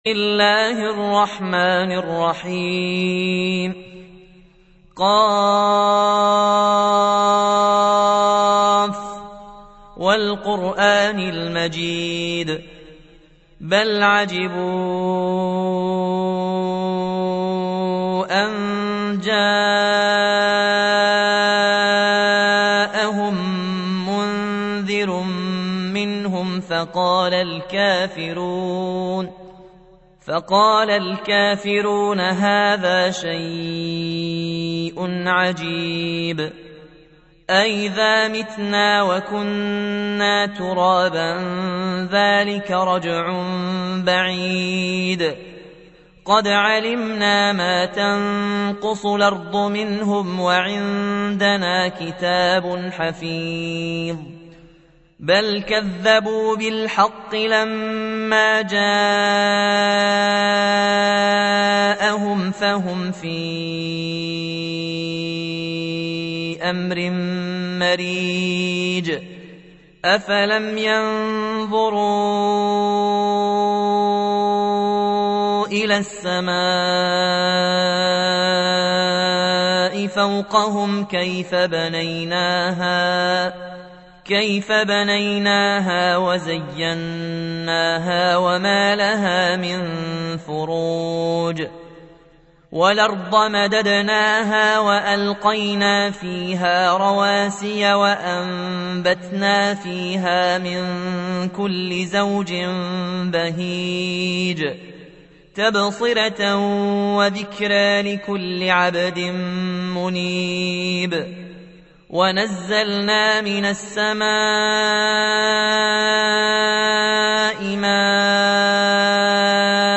تحميل : 50. سورة ق / القارئ ياسين الجزائري / القرآن الكريم / موقع يا حسين